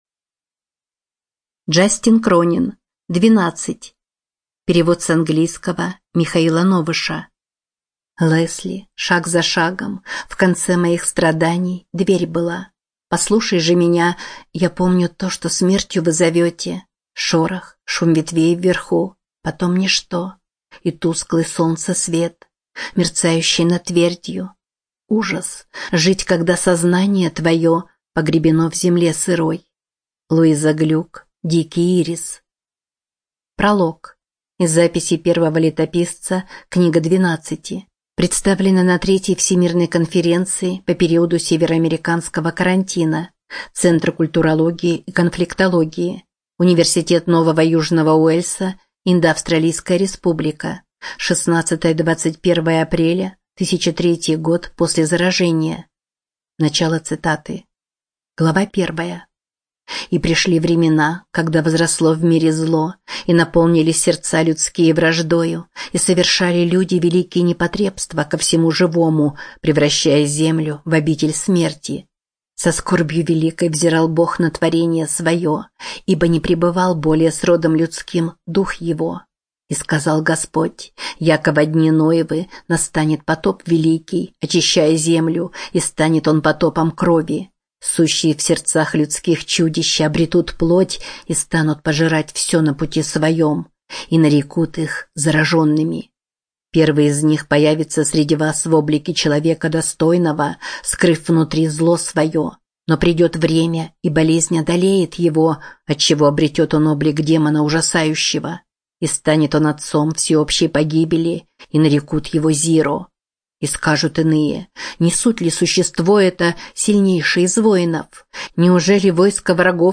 ЖанрФантастика, Ужасы и мистика